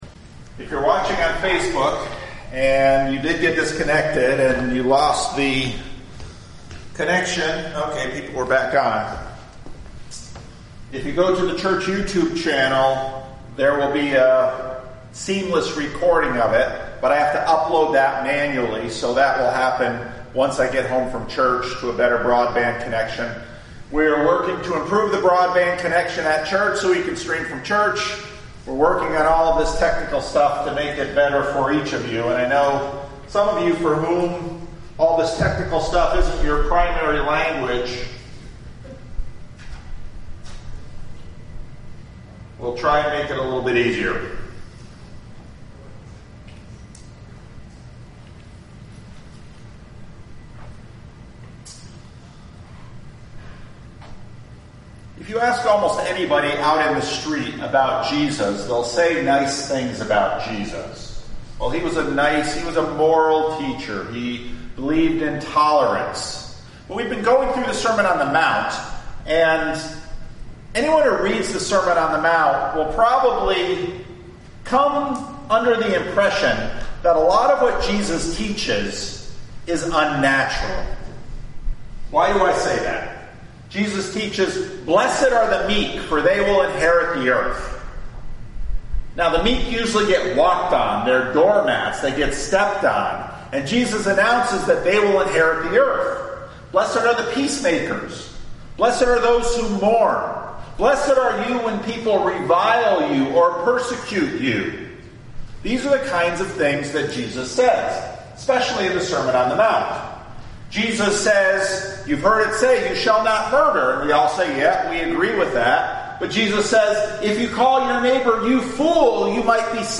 Sermons | Living Stones Christian Reformed Church